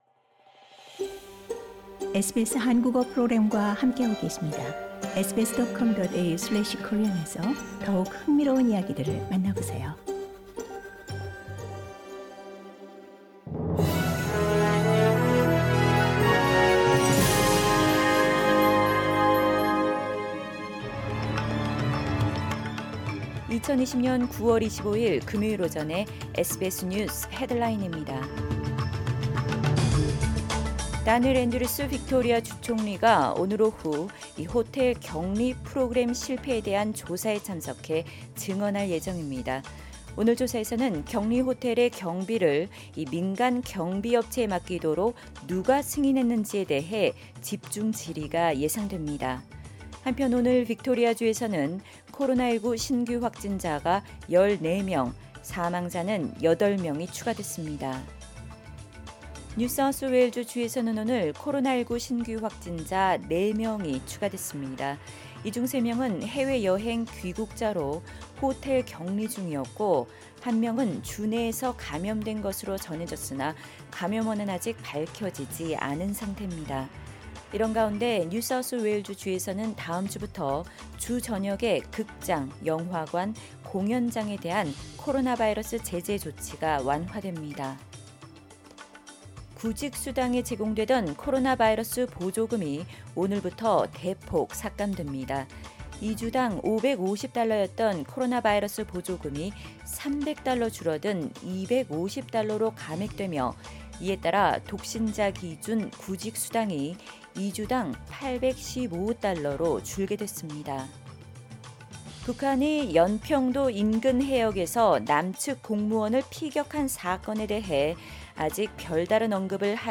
2020년 9월 25일 금요일 오전의 SBS 뉴스 헤드라인입니다.